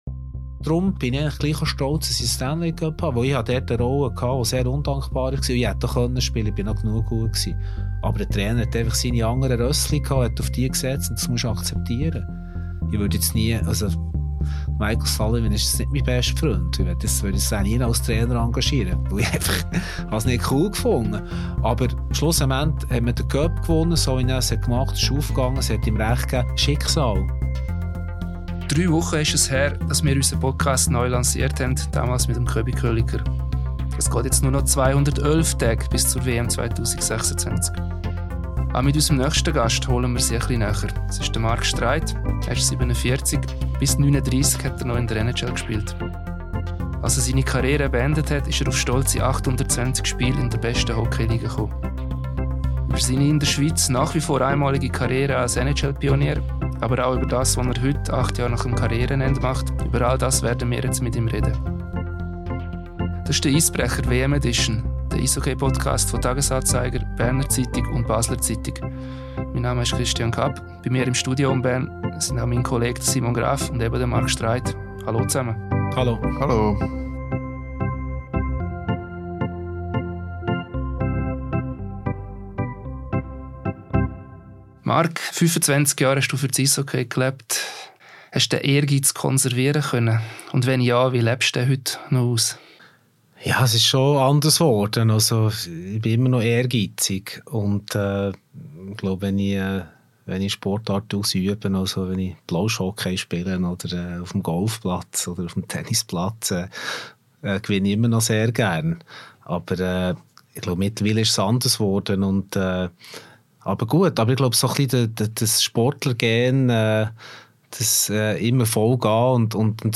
Im neuen «Eisbrecher – WM-Edition» diskutieren wir alle drei Wochen mit Protagonisten des Schweizer Eishockeys.
Unser zweiter Gast ist Mark Streit. Der frühere Verteidiger ist der Schweizer NHL-Pionier, obwohl er alles andere als einen optimalen Start in seine Karriere hatte.